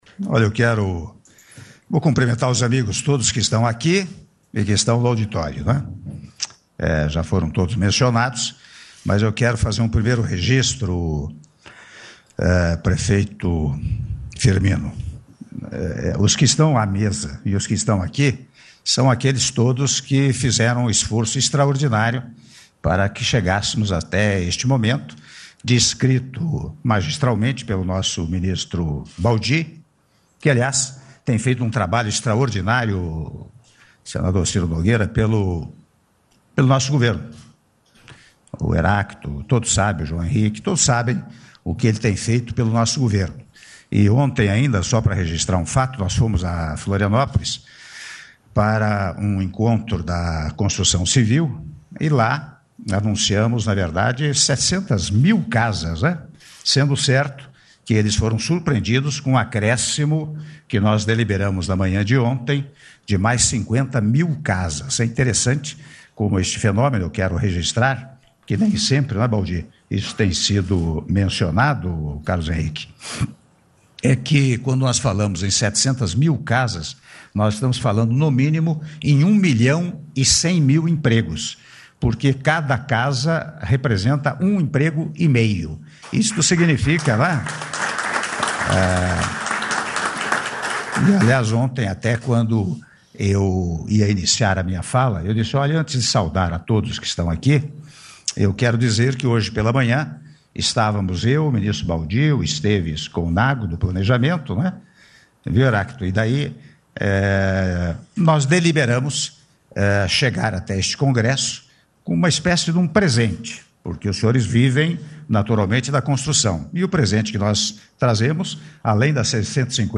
Áudio do discurso do Presidente da República, Michel Temer, durante assinatura de autorização para emissão de terreno para saneamento integrado - Brasília/DF (06min34s)